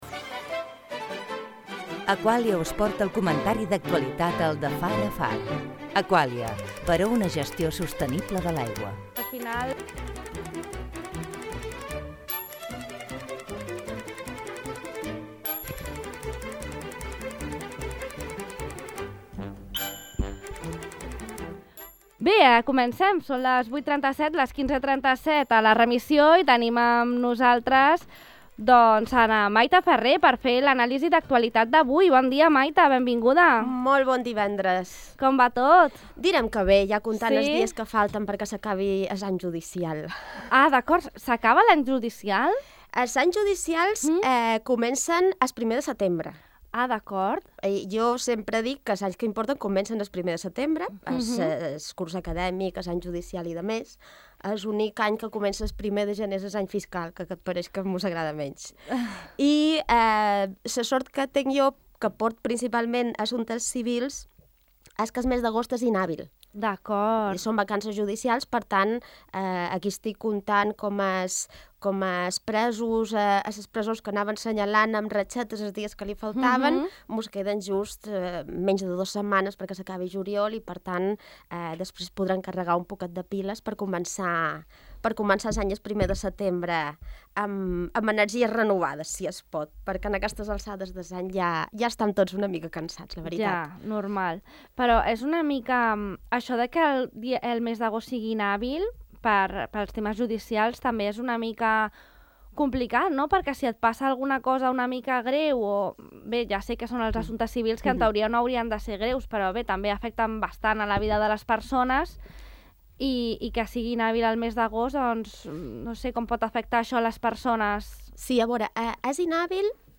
Comentari d'actualitat